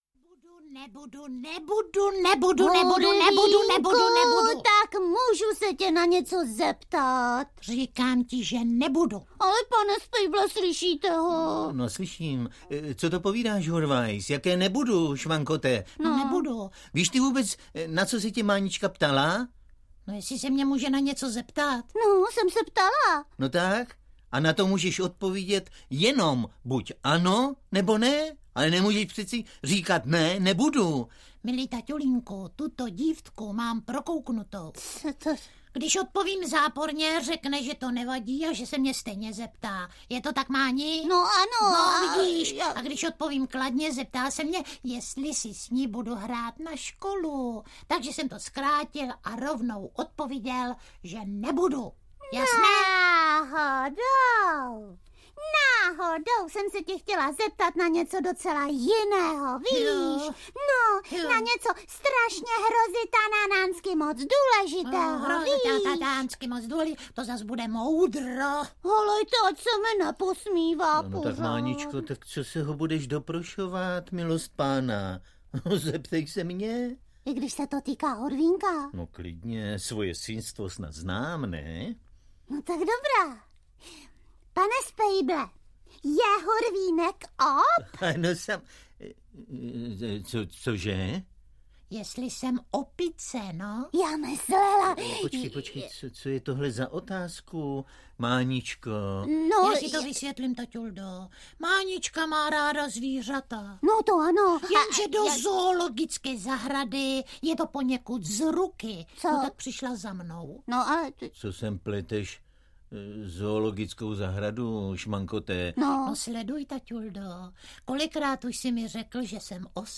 Audiokniha
Čte: Miloš Kirschner, Helena Stachová